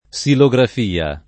vai all'elenco alfabetico delle voci ingrandisci il carattere 100% rimpicciolisci il carattere stampa invia tramite posta elettronica codividi su Facebook silografia [ S ilo g raf & a ] o xilografia [ k S ilo g raf & a ] s. f.